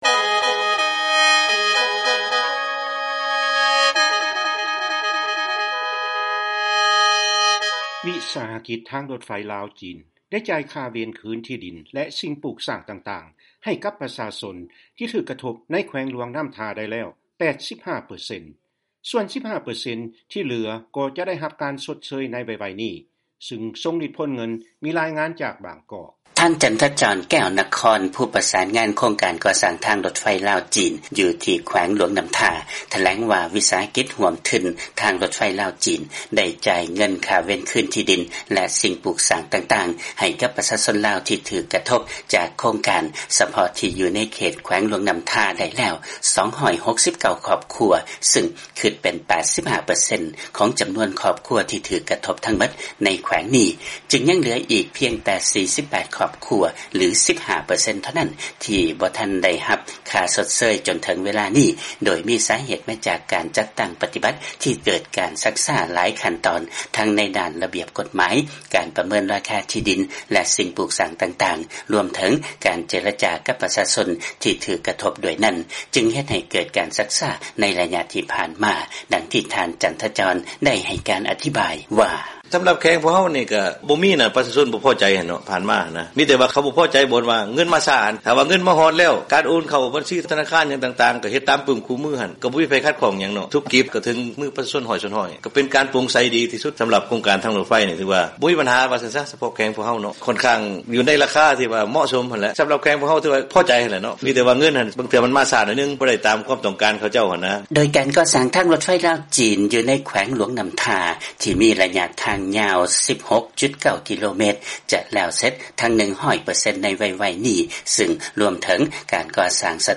ລາຍງານ ວິສາຫະກິດທາງລົດໄຟ ລາວ-ຈີນ ໄດ້ຈ່າຍເວນຄືນທີ່ດິນ ໃຫ້ກັບປະຊາຊົນທີ່ຖືກກະທົບໄປແລ້ວສ່ວນນຶ່ງ